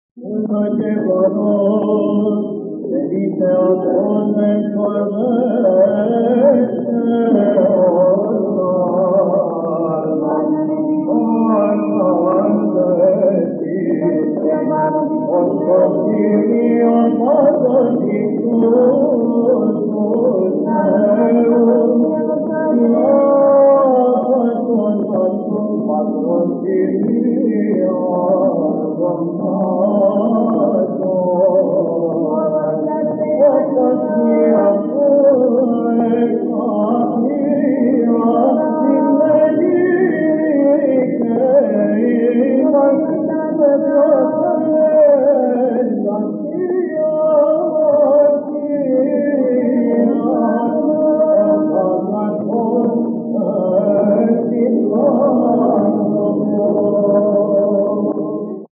(ἠχογρ. Κυρ. Βαΐων ἑσπέρας)